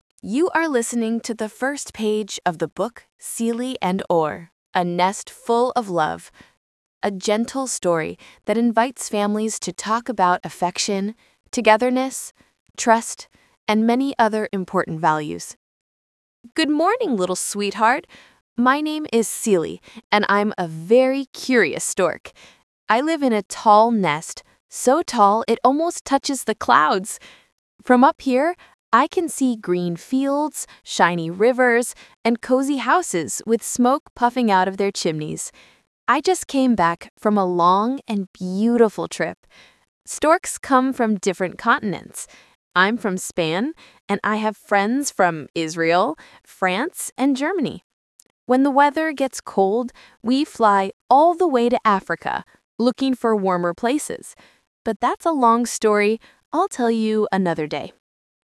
Readings (audio)